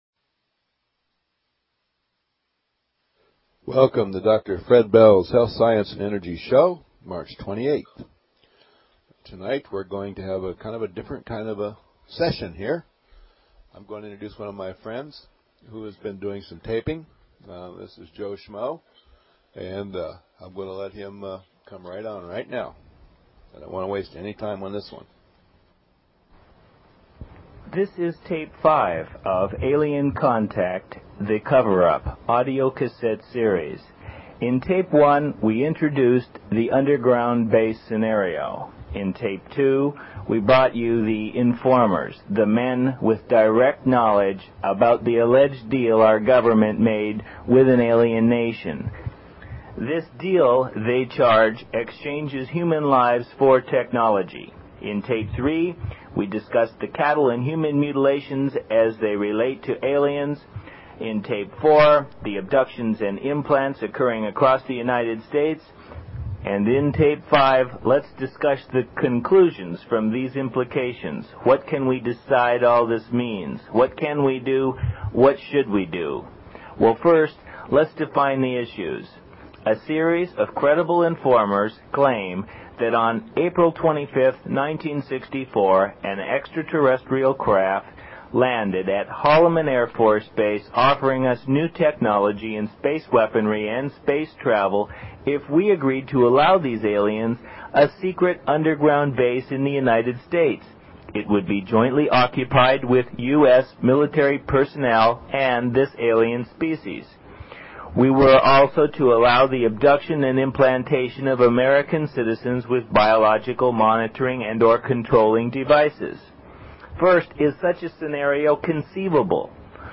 Talk Show Episode, Audio Podcast, Dr_Bells_Health_Science_and_Energy_Show and Courtesy of BBS Radio on , show guests , about , categorized as